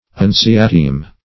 unciatim - definition of unciatim - synonyms, pronunciation, spelling from Free Dictionary Search Result for " unciatim" : The Collaborative International Dictionary of English v.0.48: Unciatim \Un`ci*a"tim\, adv.